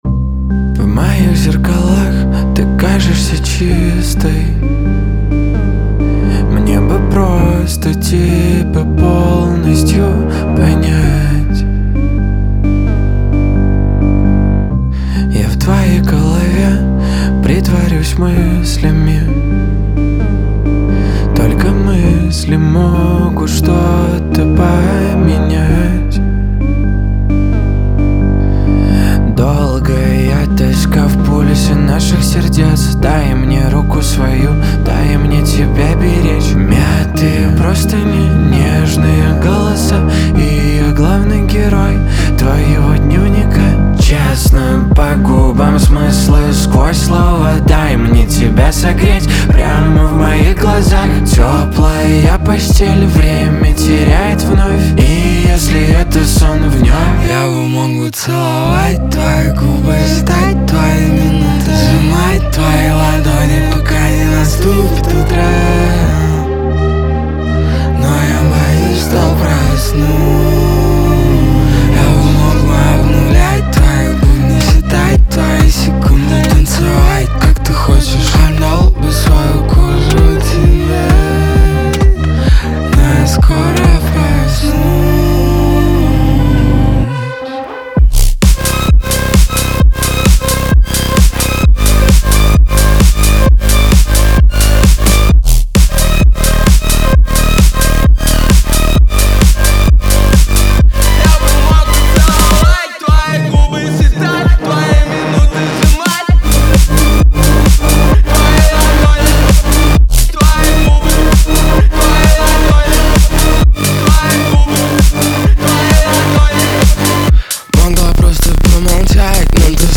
pop
дуэт
эстрада